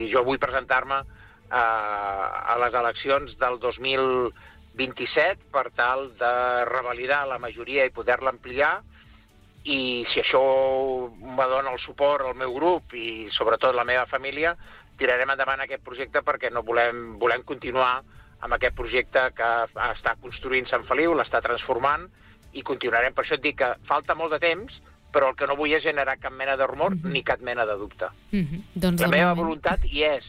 En una entrevista concedida al programa Supermatí, l’alcalde de Sant Feliu de Guíxols, Carles Motas, ha confirmat que té la voluntat de tornar-se a presentar a les eleccions municipals de 2027.